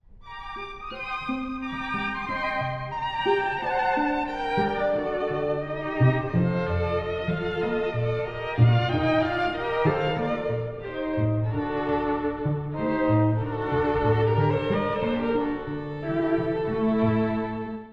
(第一主題) 古い音源なので聴きづらいかもしれません！
主部は陰鬱で、ある種の緊張感もあります。